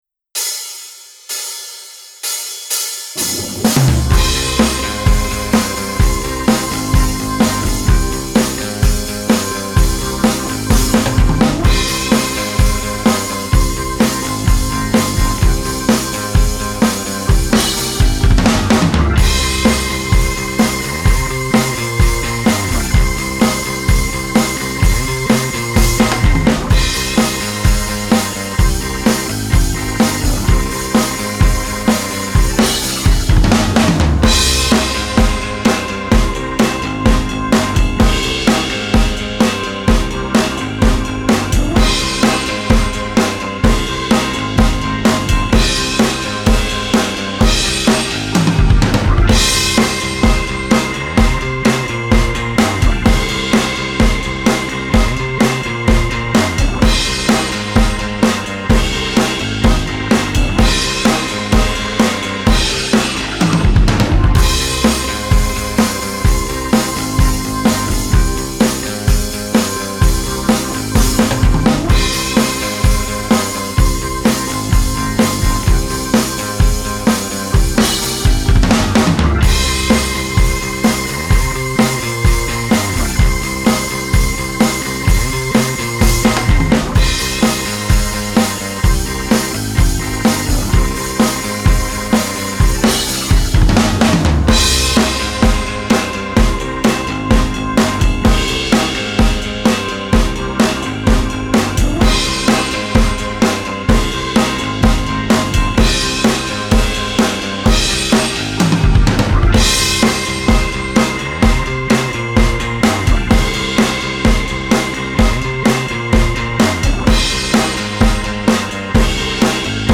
Guitar Lessons: Meat and Potatoes: Standard Rock Licks